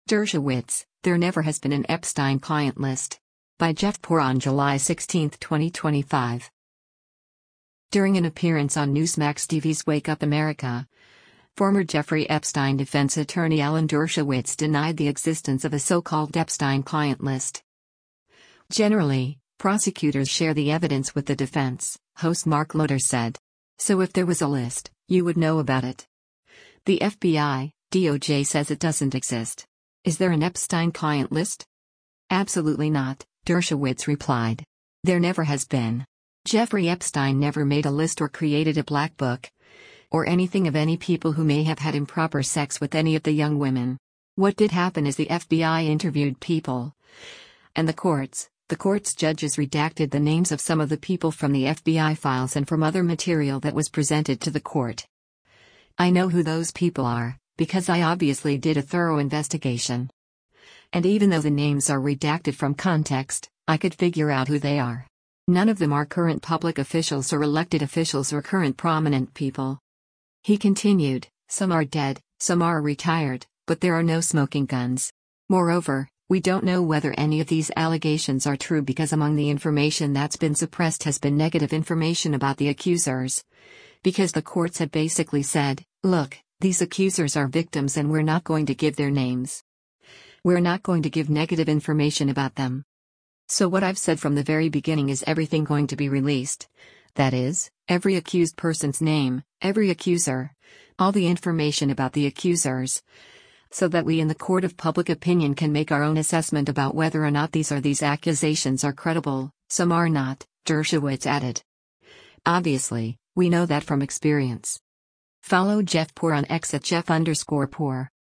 During an appearance on Newsmax TV’s “Wake Up America,” former Jeffrey Epstein defense attorney Alan Dershowitz denied the existence of a so-called Epstein client list.